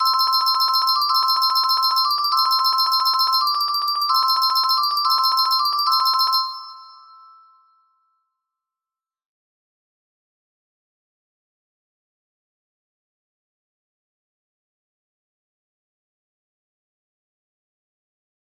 up down pt 2 music box melody